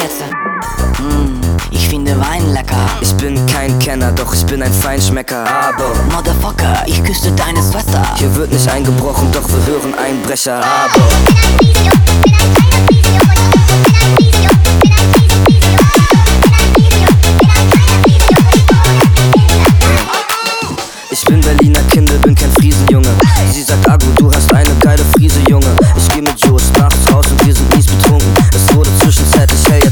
Жанр: Пост-хардкор / Хард-рок